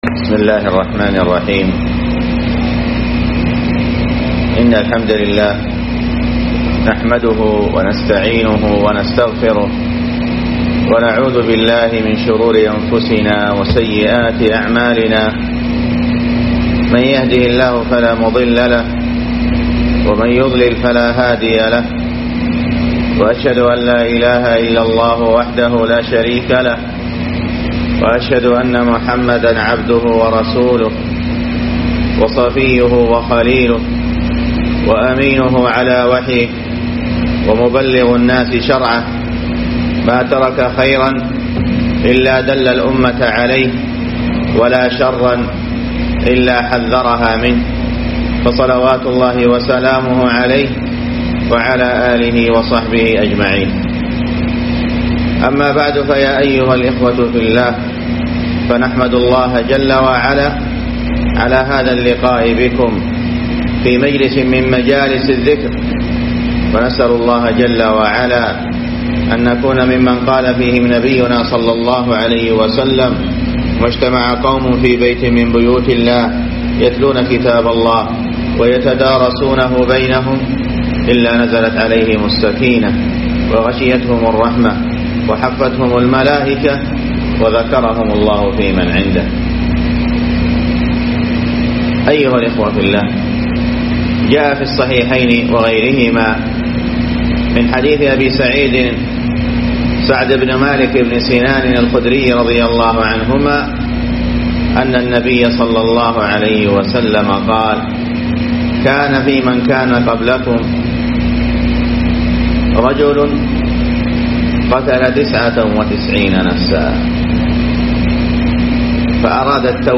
محاضرة
جامع البيسري بمركز السهي صامطة